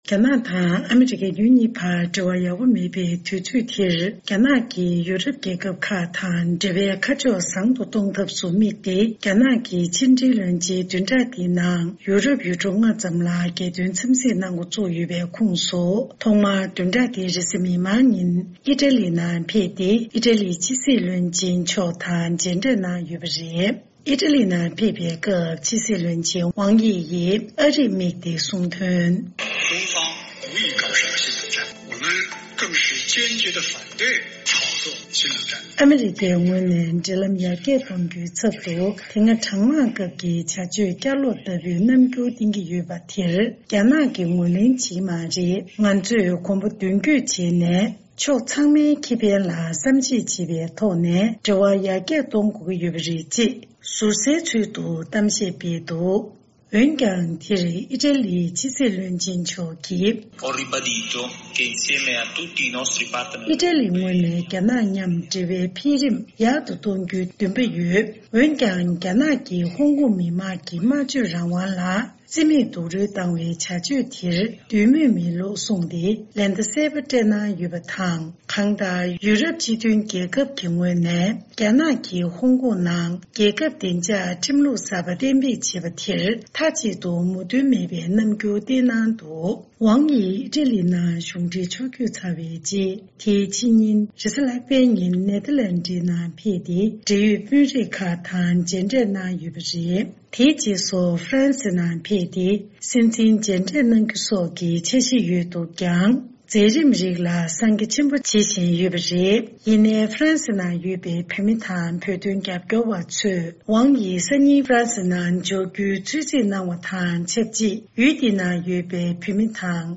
འབྲེལ་ཡོད་མི་སྣར་གནས་འདྲི་ཞུས་སྟེ་གནས་ཚུལ་ཕྱོགས་སྒྲིག་ཞུས་པ་དེ་གསན་རོགས་གནང་།